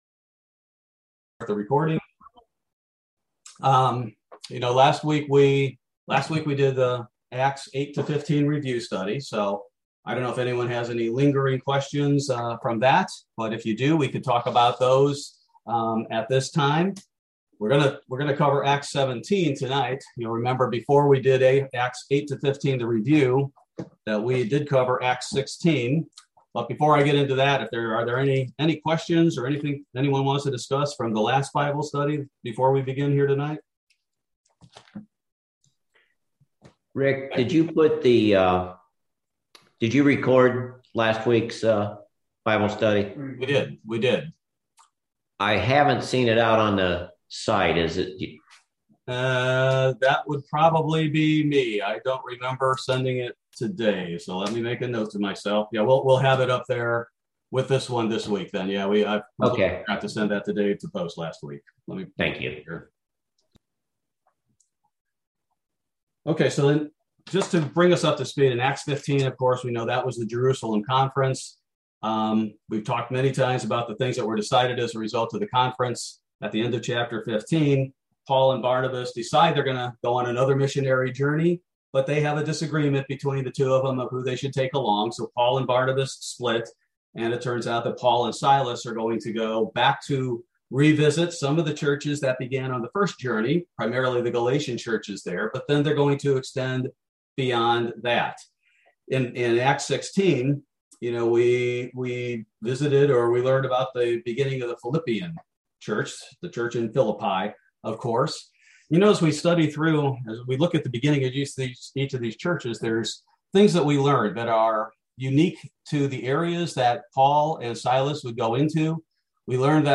Bible Study: November 3, 2021